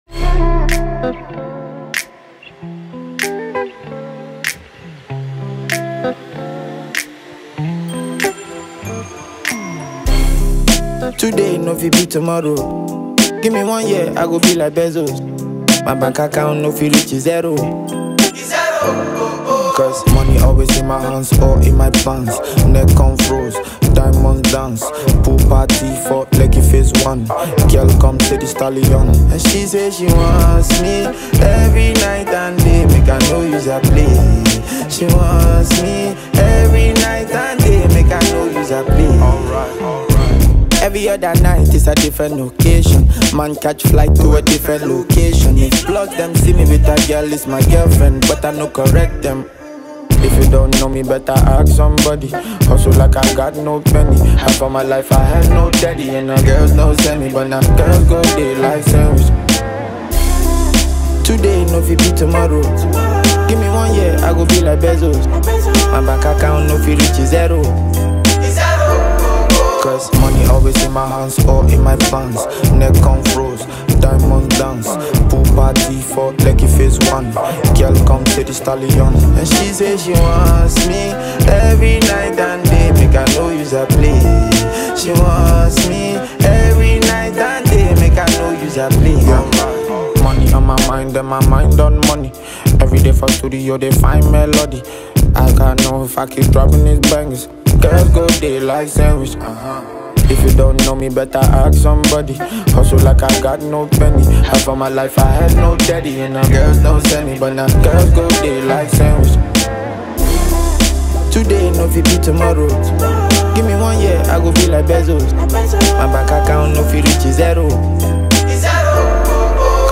” with some catchy flows.
freestyles
switching flows on his rhythmic verses.